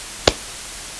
clap-04_noise0.02.wav